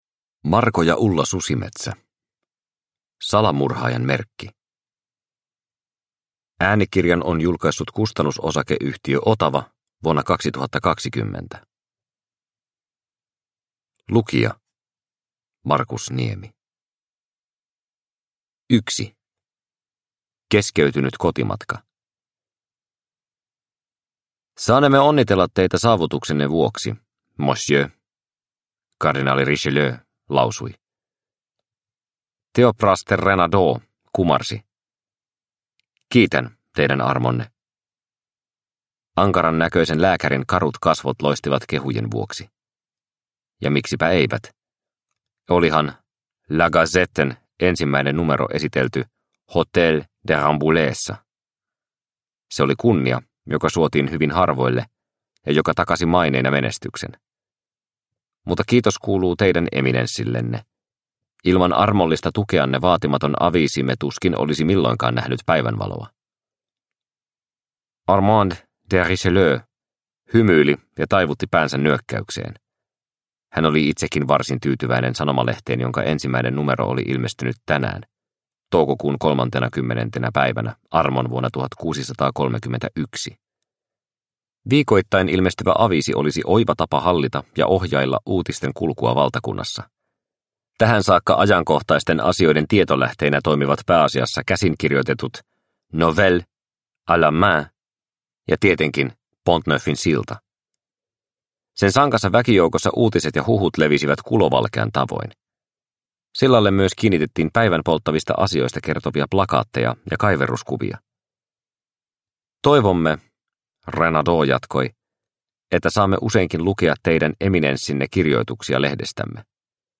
Salamurhaajan merkki – Ljudbok – Laddas ner